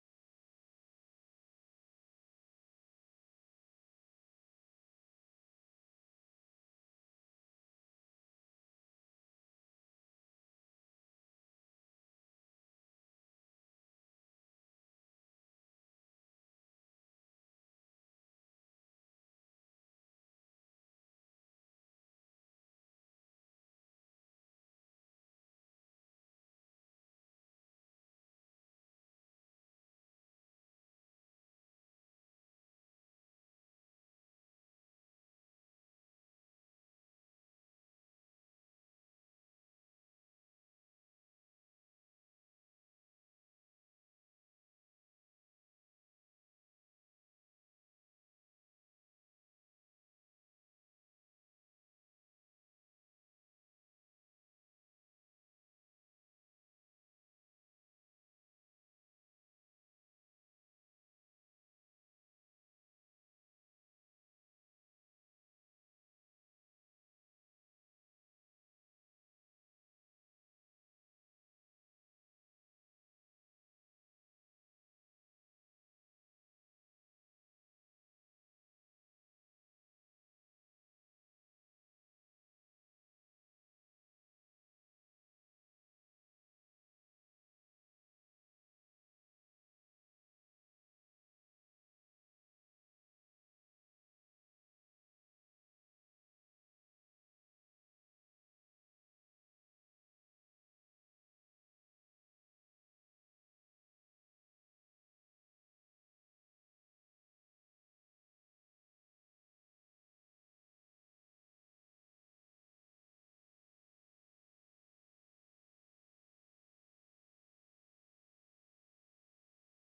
Our very talented worship team brings it home again.